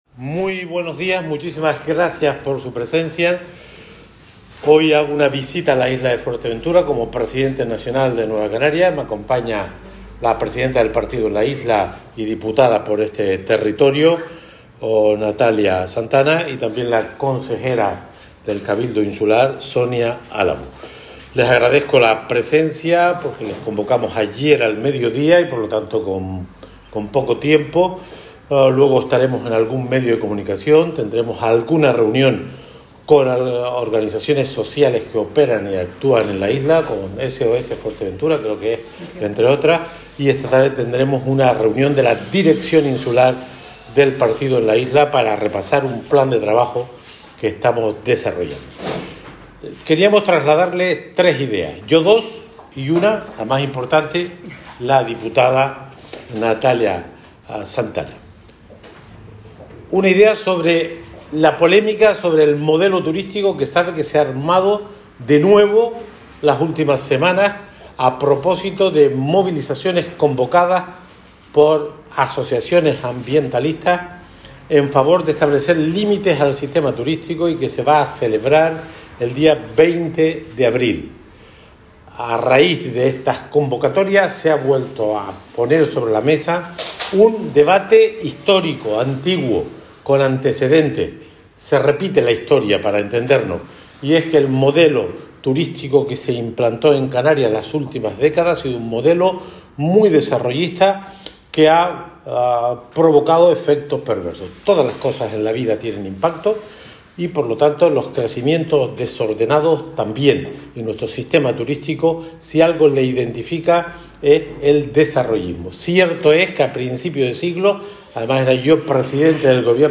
Román Rodríguez, Natalia Santana y Sonia Álamo presentan, en Puerto del Rosario, la iniciativa que se defenderá en el próximo pleno de la Cámara